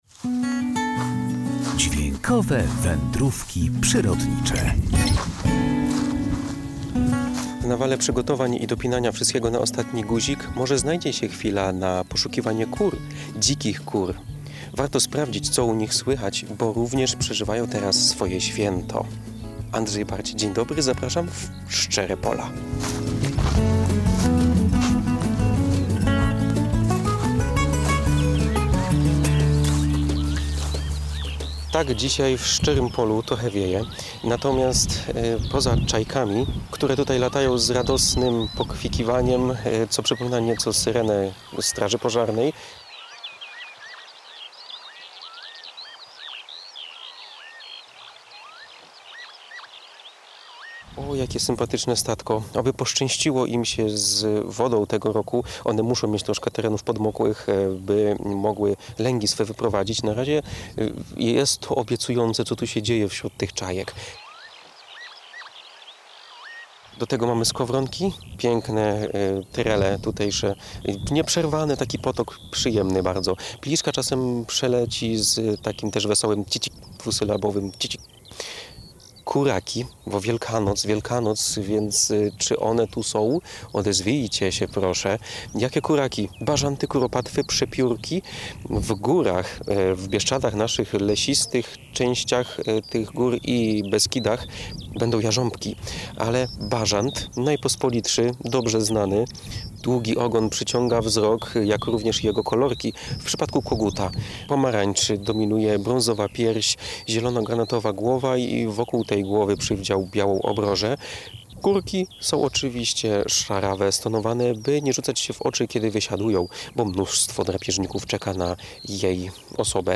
Dźwiękowe Wędrówki Przyrodnicze • W nawale przedświątecznych przygotowań i dopinaniu wszystkiego na ostatni guzik mogliśmy zapomnieć, co działo się za naszymi oknami. Może teraz znajdzie się chwila lub dwie na poszukanie kur - polnych kur?